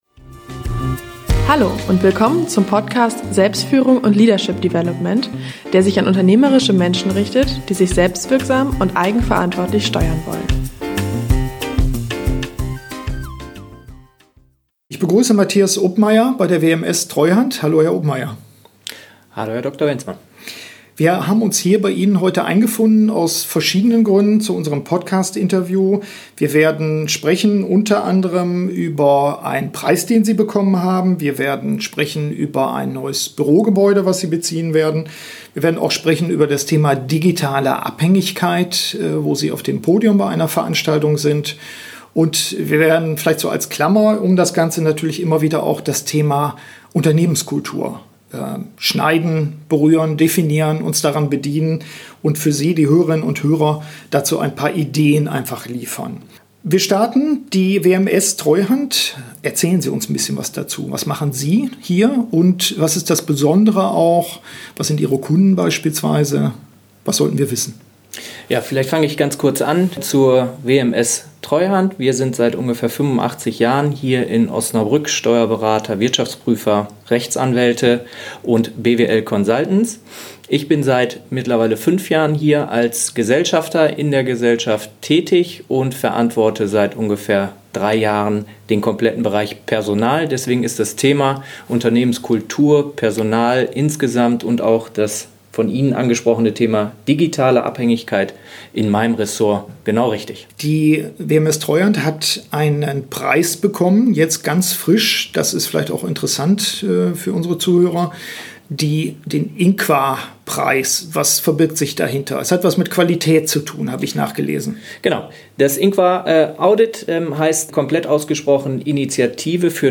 Im Interview erläutert er, wie das Unternehmen sich durch ein Audit reflektiert und weiter entwickelt.